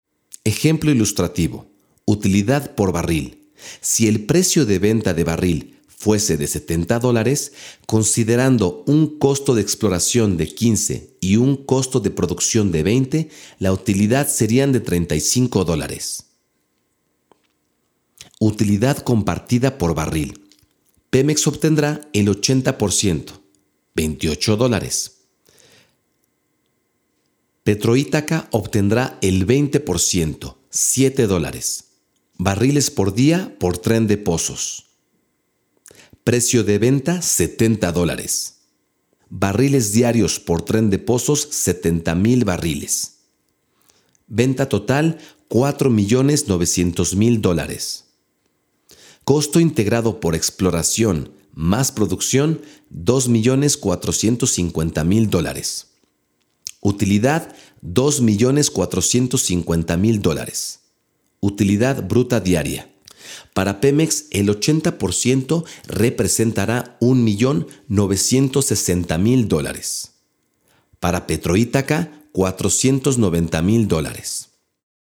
Spanish and english speaker, I'm a professional voice over actor.
Sprechprobe: eLearning (Muttersprache):